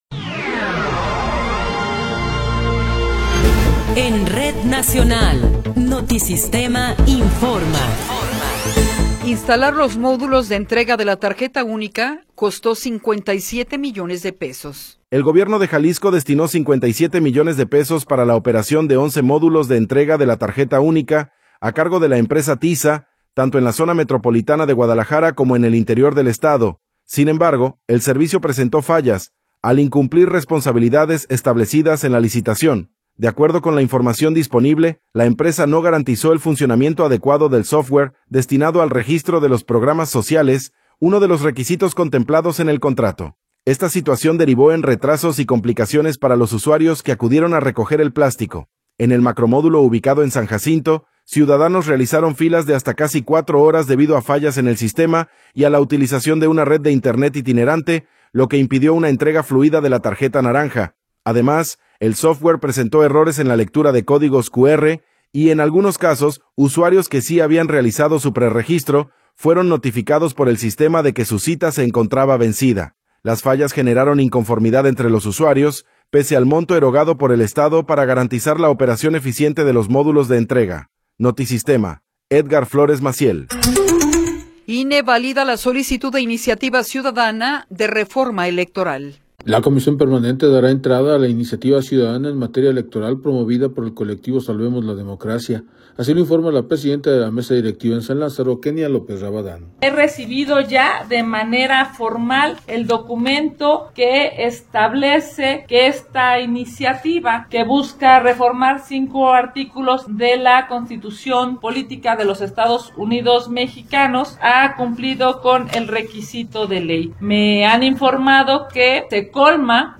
Noticiero 16 hrs. – 19 de Enero de 2026